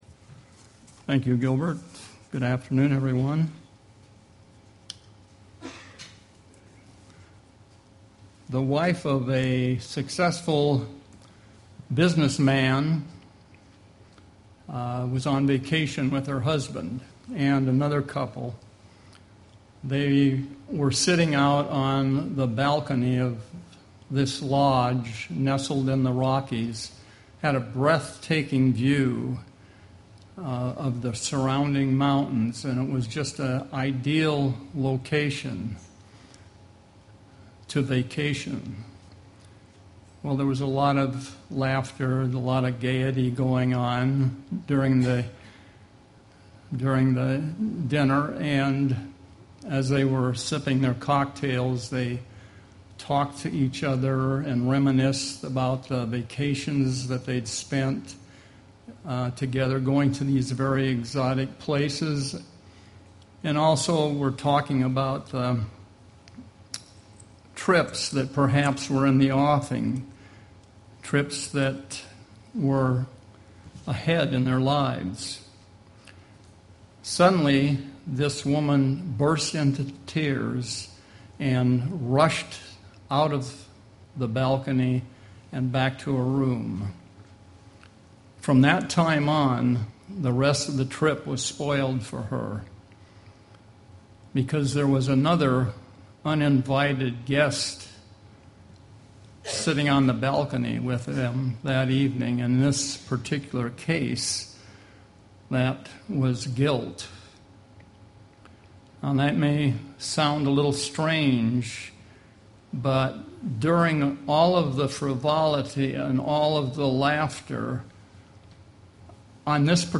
Print UCG Sermon Studying the bible?